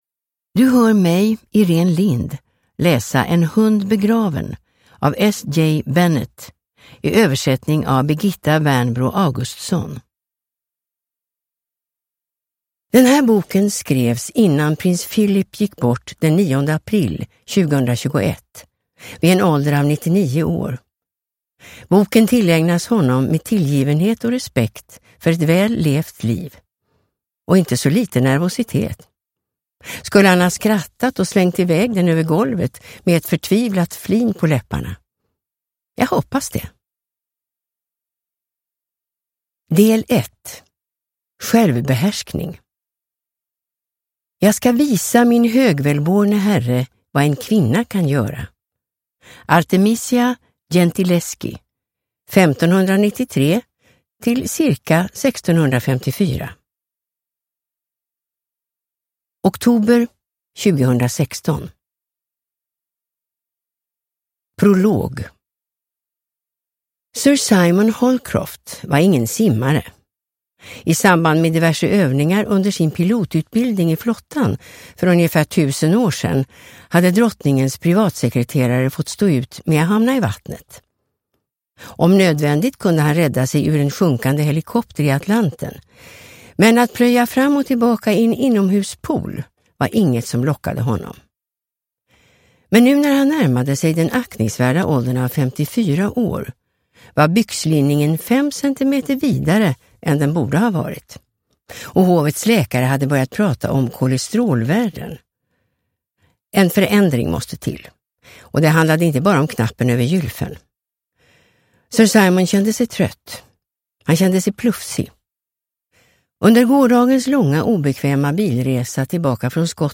En hund begraven – Ljudbok – Laddas ner